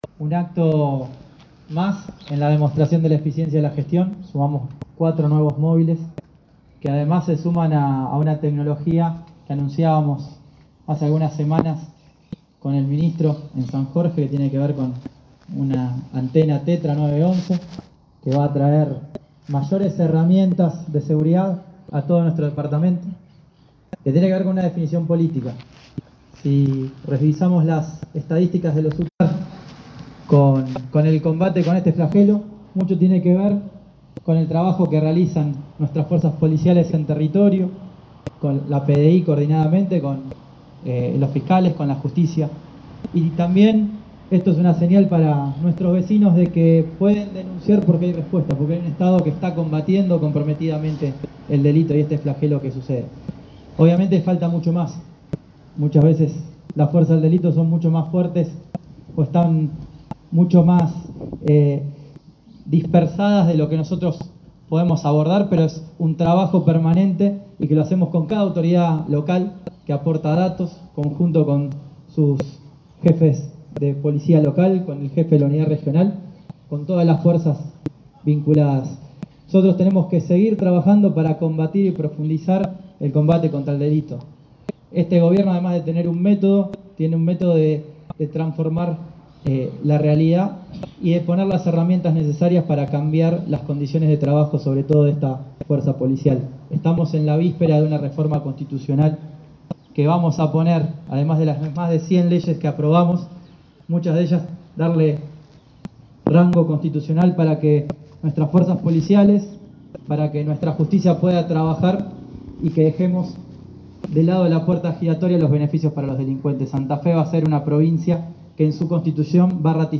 En un acto  para la seguridad del departamento San Martín, el gobierno provincial concretó la entrega de cuatro camionetas Fiat Toro totalmente equipadas a la Unidad Regional XVIII.